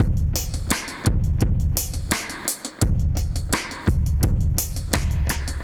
Index of /musicradar/dub-designer-samples/85bpm/Beats
DD_BeatD_85-02.wav